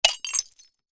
Звук разбитого сердца надвое